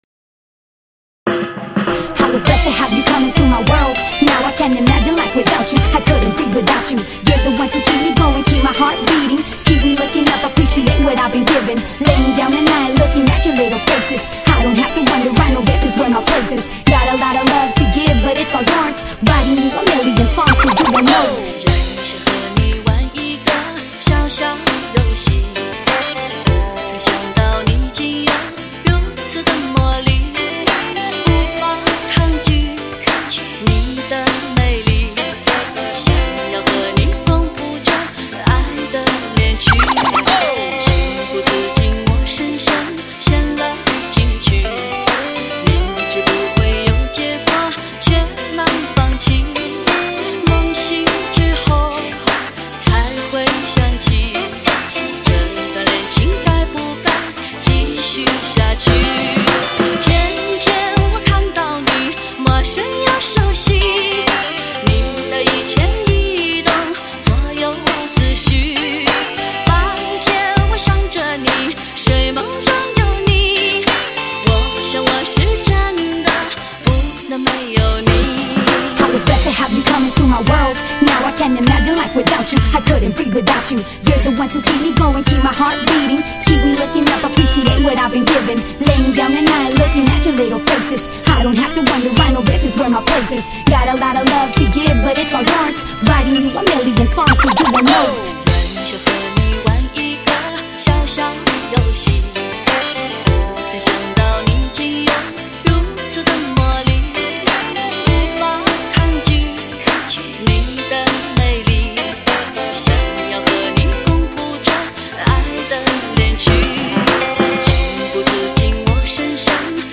舞曲音乐，震撼心灵，给汽车乐迷前所未有的新感受。
流行音乐，唯美动听，乐曲音乐，浓浓韵味。